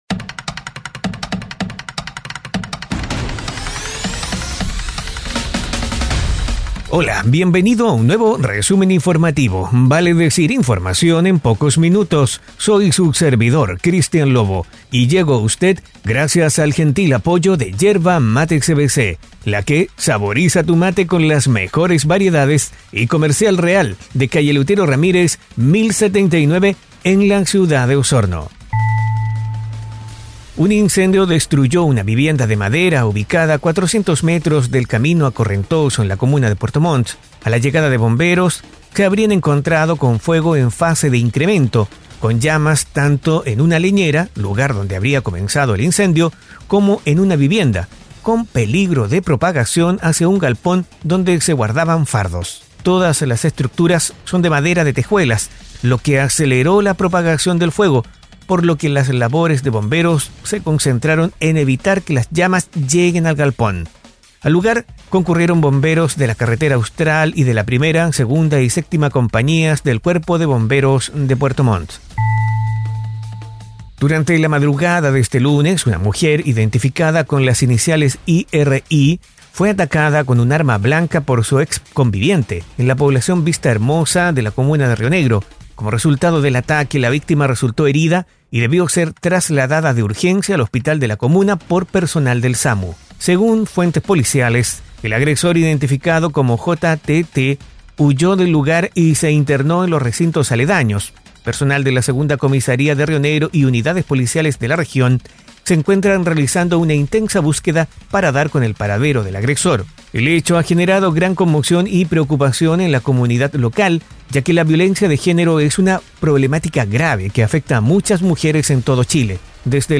🐺Resumen informativo es un audio podcast con una decena de informaciones en pocos minutos